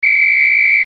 SFX紧急吹哨子的音效下载
SFX音效